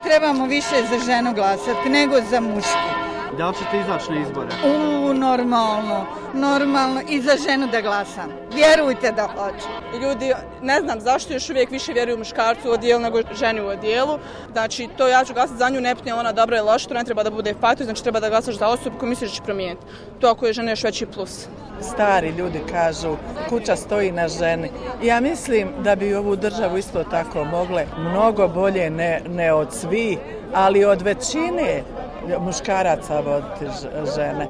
Anketa da li će žene glasati za žene